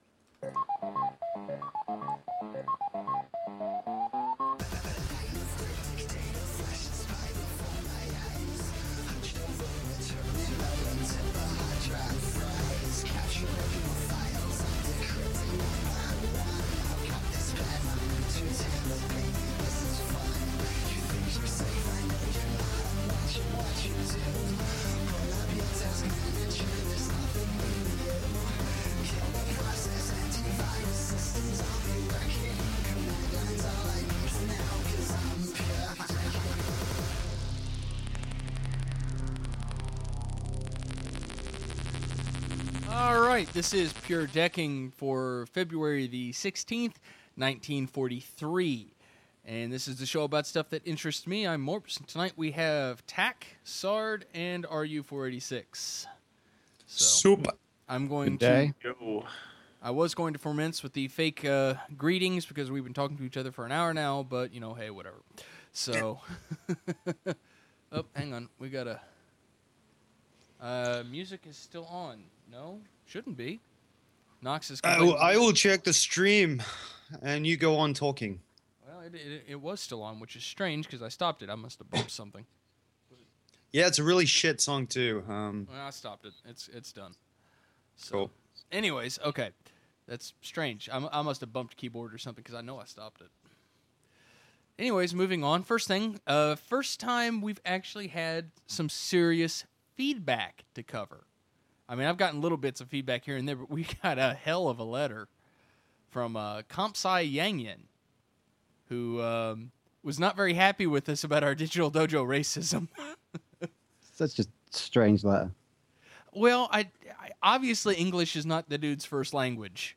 live show